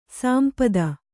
♪ sāmpada